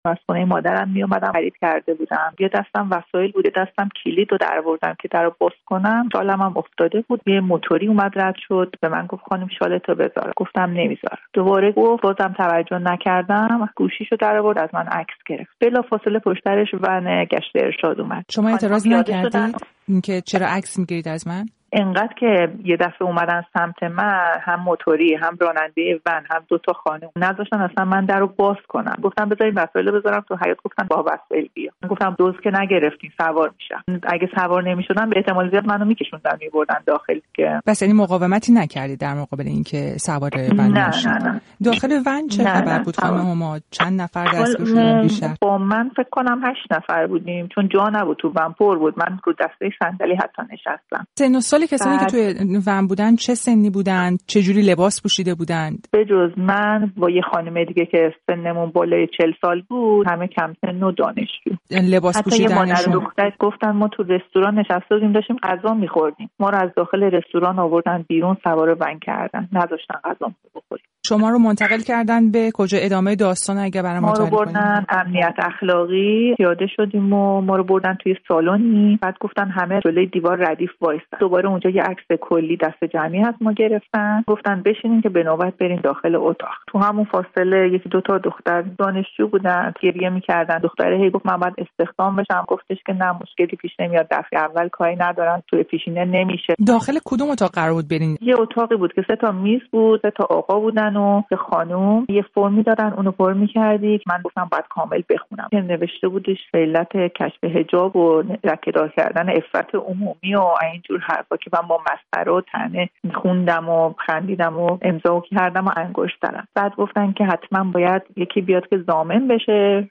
گفت‌وگو با یک شهروند که به‌خاطر حجابش بازداشت شد